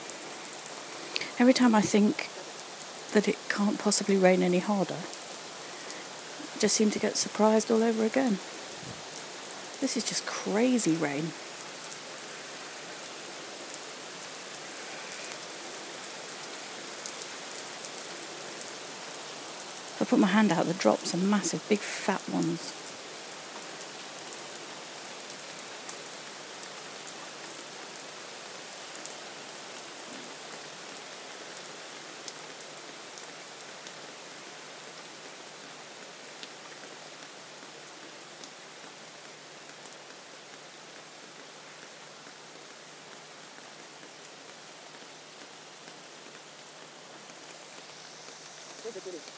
Fat rain